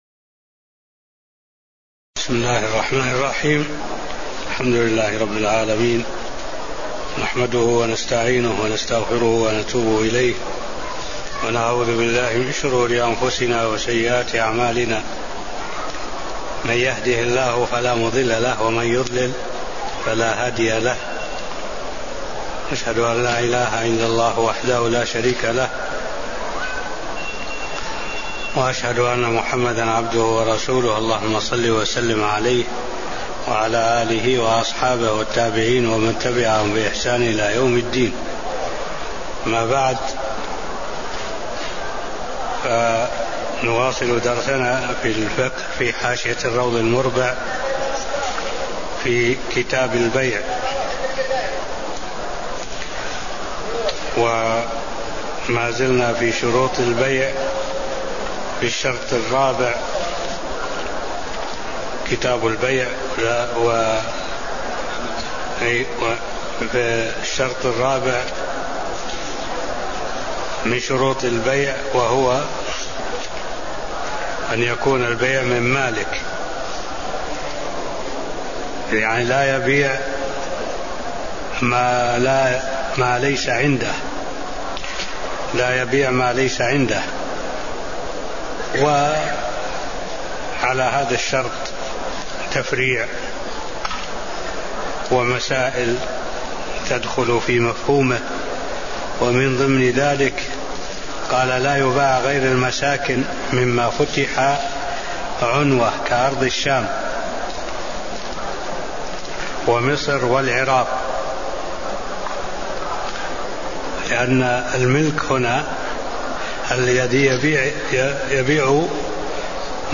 تاريخ النشر ٣٠ محرم ١٤٢٨ هـ المكان: المسجد النبوي الشيخ: معالي الشيخ الدكتور صالح بن عبد الله العبود معالي الشيخ الدكتور صالح بن عبد الله العبود شروط البيع الشرط الرابع (010)